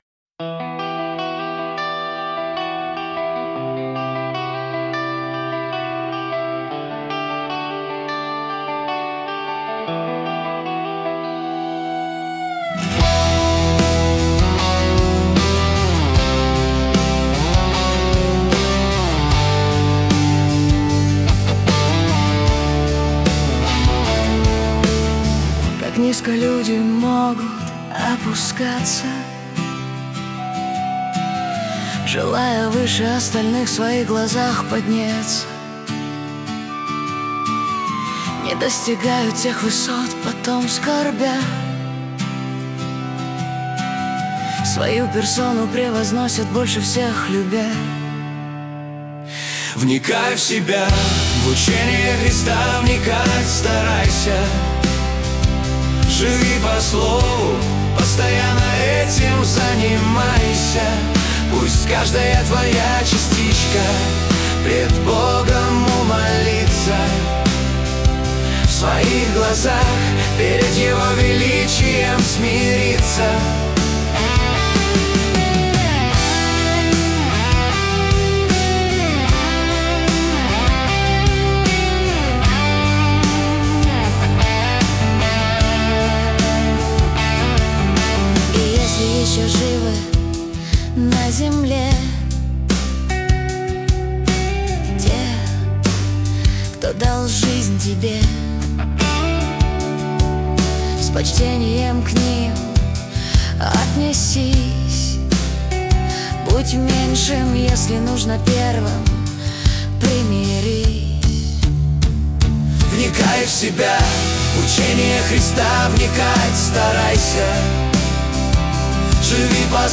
песня ai
Em/C/D/Em/C/G/D
115 просмотров 497 прослушиваний 51 скачиваний BPM: 76